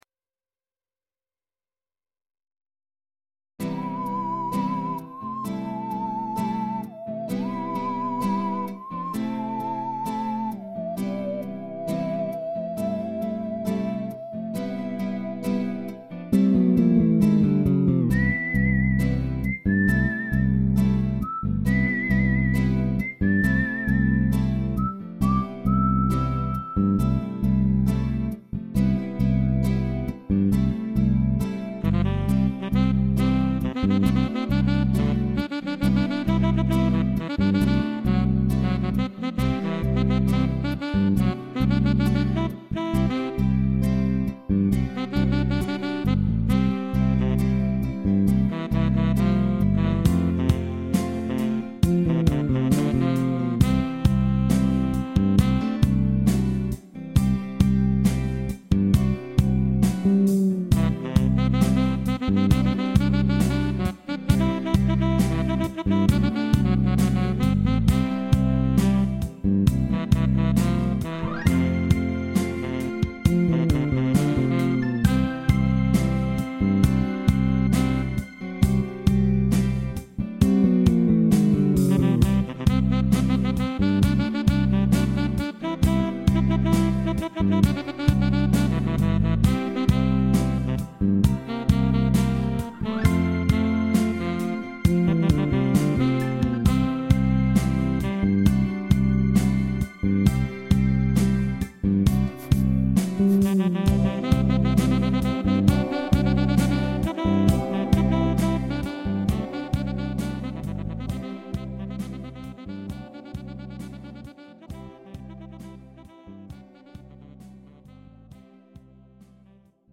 Ballads Music